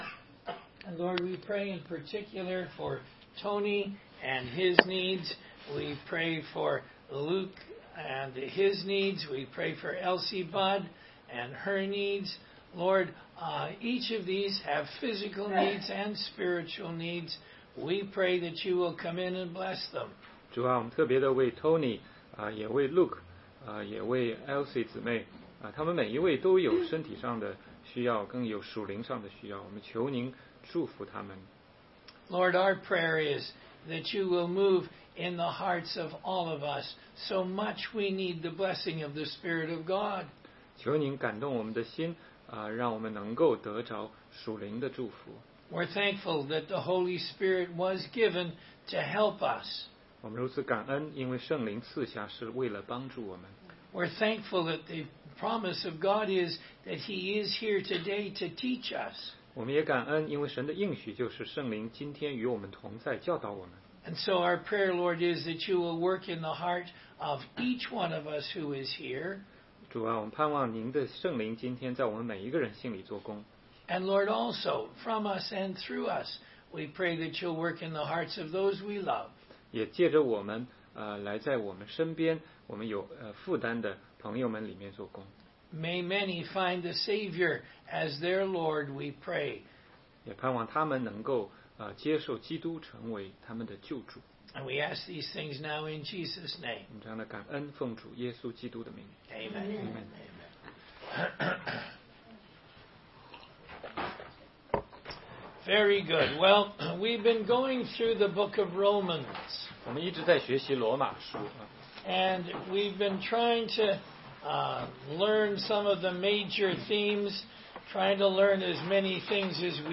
16街讲道录音 - 罗马书11章25-27：以色列全家都要得救